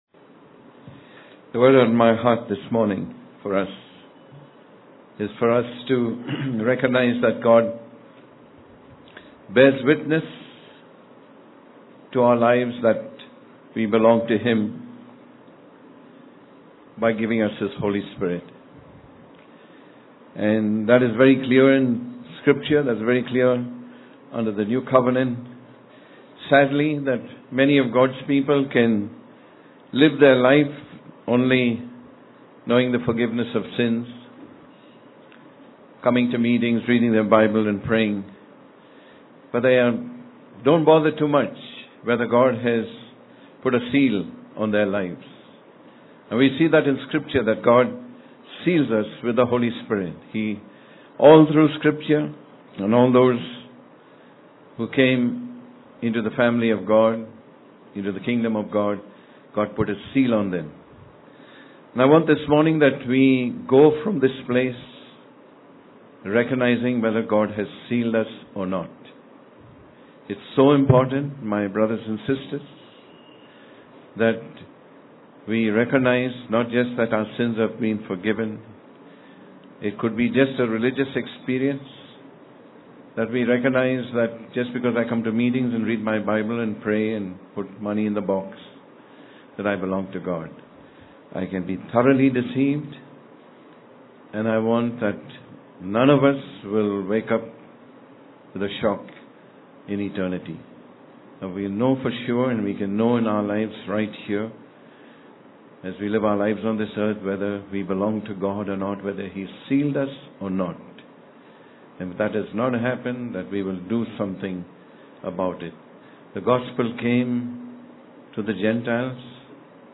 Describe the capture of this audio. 15th, April, 2012 - Child Dedication